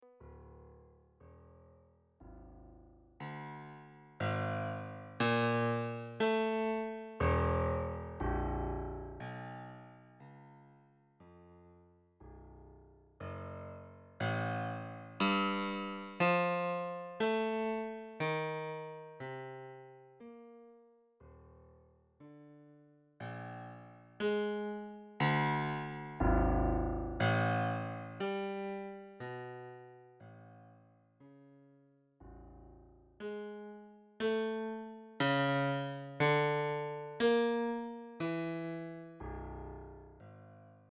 以下の例では、ノード番号などを伏せて次々にサウンドを生成している。
こんな音がした。